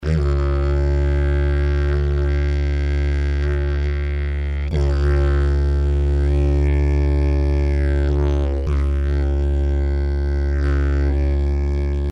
Диджериду (ветла, тон С#)
Длина (см): 163
Дидж со средней отзывчивостью, но с очень низким, мягким, обволакивающе-медитативным звучанием.